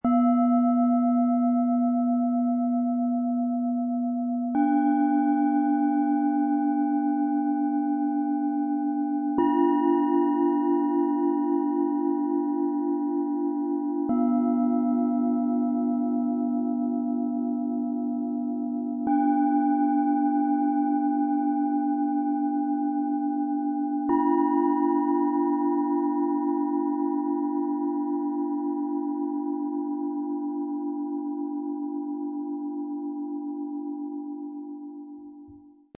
Zentriert im Jetzt - Klangreise nach oben - Set aus 3 Klangschalen, Ø 12,6 - 13,6 cm, 1,03 kg
Diese drei Klangschalen bilden den aufsteigenden Klangweg:
Tiefster Ton – ruhig, tief, zentrierend
Mittlerer Ton – freundlich, offen, herzzentriert
Ihr Klang wirkt sanft, aktivierend und emotional öffnend.
Höchster Ton – klar, leicht, aufsteigend
Diese Schale klingt hell und weit.
Der im Lieferumfang enthaltene Klöppel bringt die feinen, klaren Töne dieses Sets wunderbar zur Geltung.
MaterialBronze